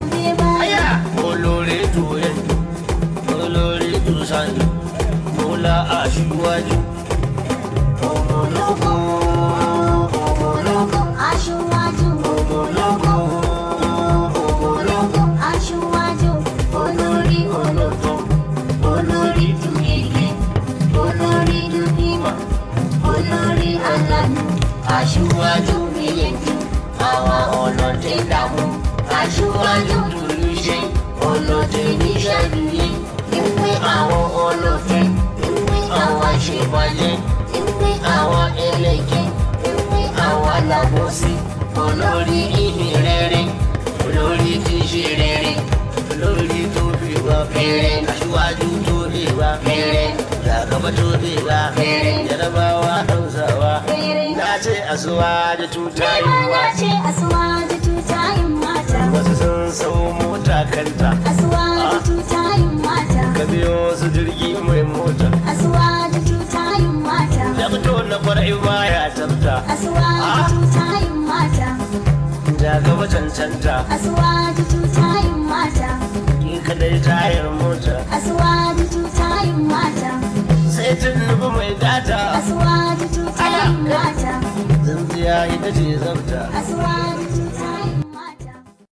Hausa love song